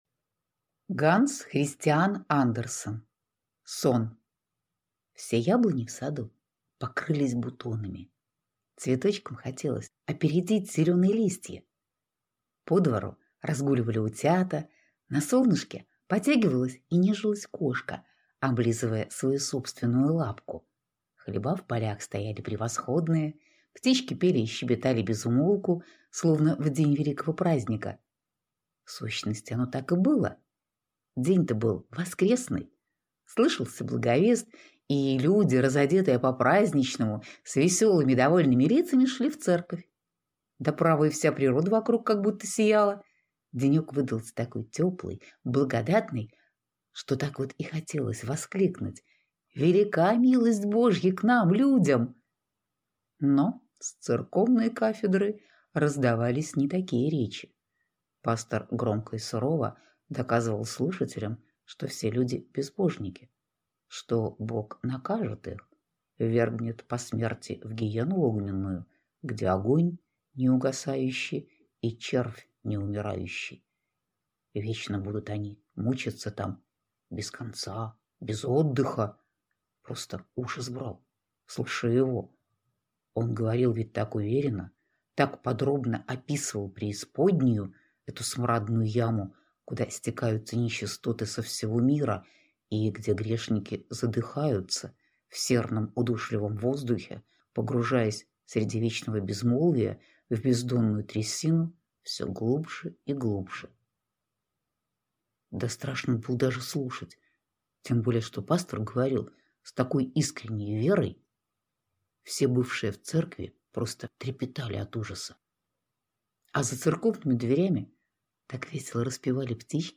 Аудиокнига Сон | Библиотека аудиокниг